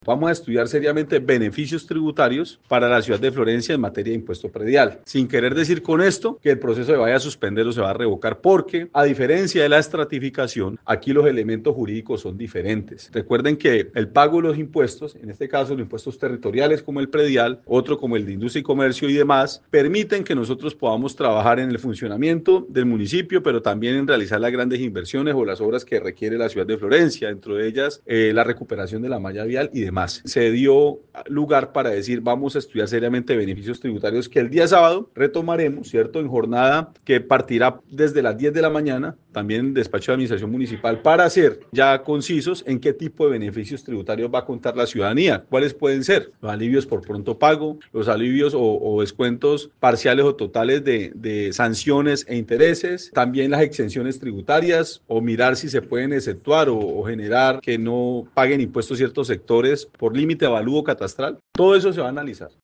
El alcalde de la ciudad explicó que, en una segunda sesión de la mesa, programada para este 15 de febrero, se analizarán posibles descuentos por pago oportuno, en intereses por mora, incluso, se piensa en excepciones a grupos poblacionales.
ALCALDE_MONSALVE_ASCANIO_PREDIAL_-_copia.mp3